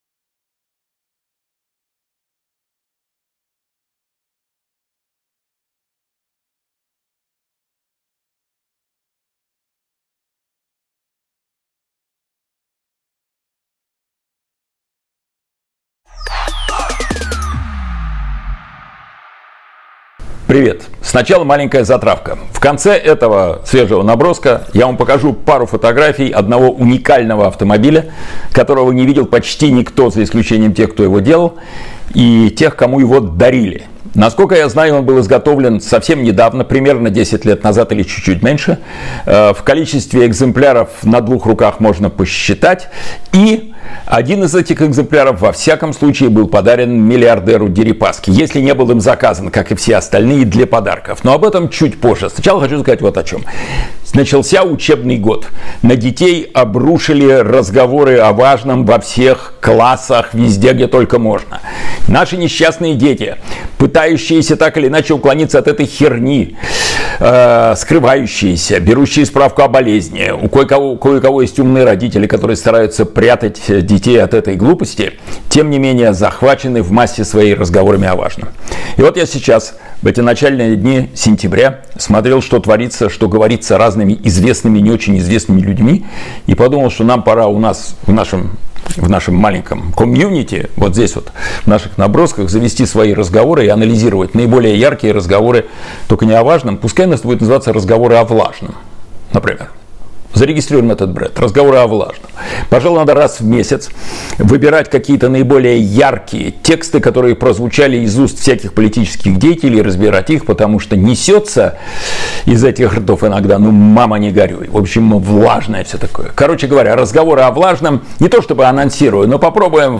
Эфир ведёт Кирилл Набутов